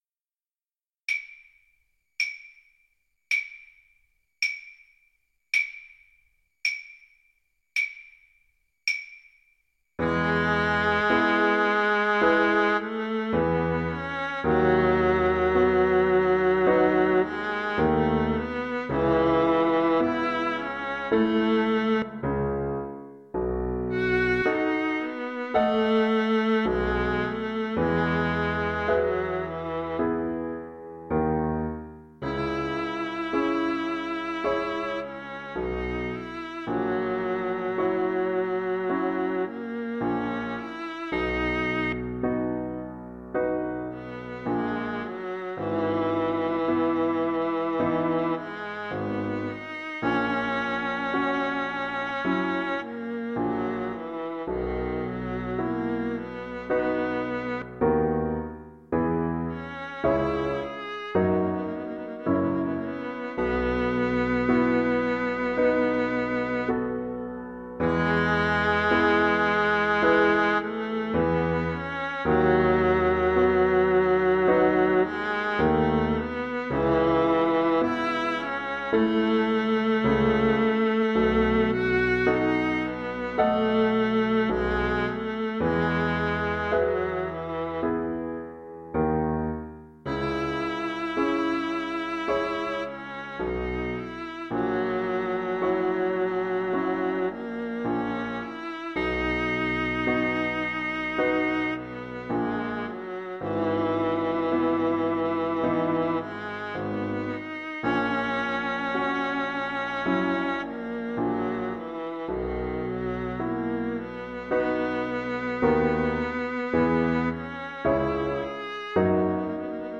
Viola version
Andante = c.76
Viola  (View more Intermediate Viola Music)
Classical (View more Classical Viola Music)